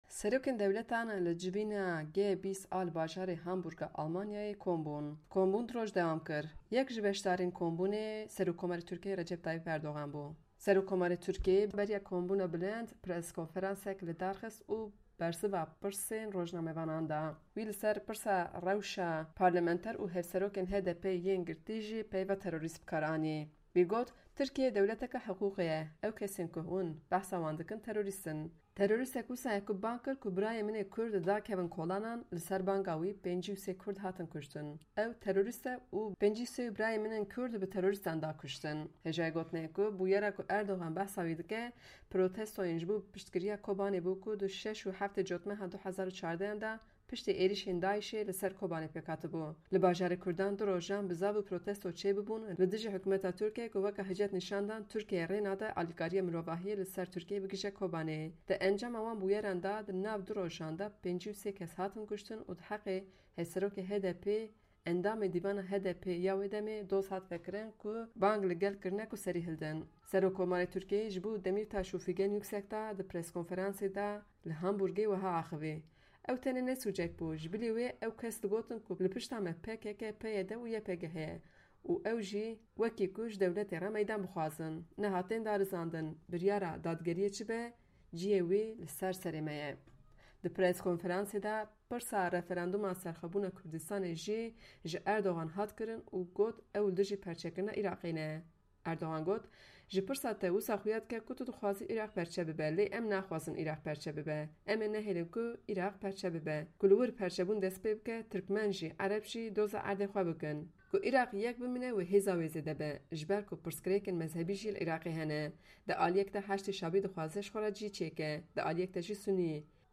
Beriya kombûna bilind, Erdogan press konferansek lidarxist û jibo hevserokê HDPê Demirtaş peyva teroris bikar anî.